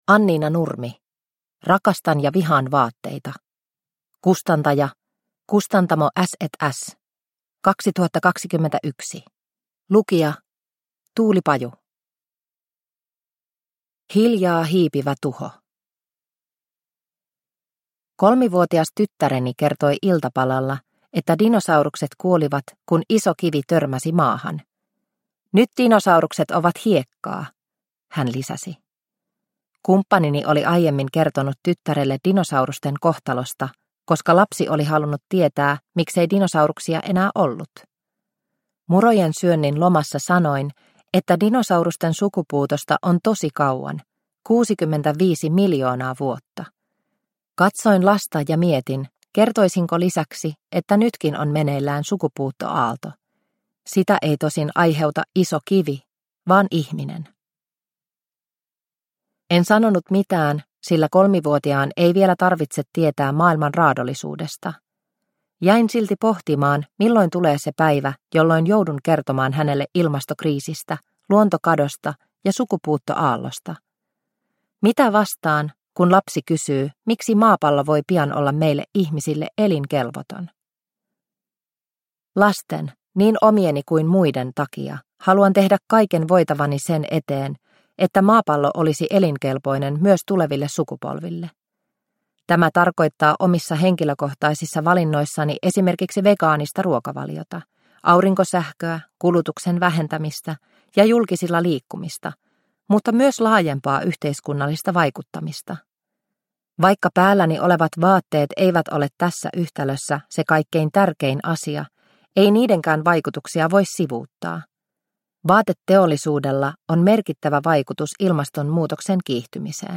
Rakastan ja vihaan vaatteita – Ljudbok – Laddas ner